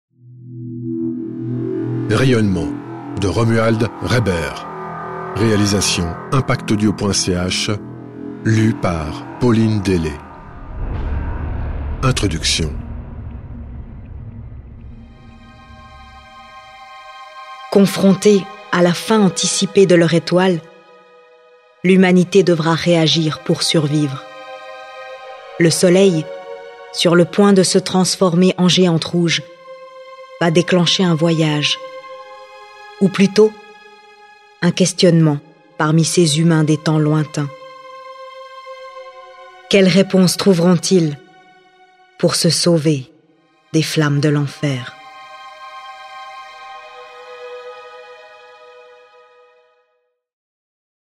Cette version audio de Rayonnement retranscrit avec panache, par des ambiances sonores très riches et savamment dosées, l’atmosphère fantastique des voyages que vont entreprendre ces hommes et femmes vivant sur la Terre juste avant la fin du Soleil.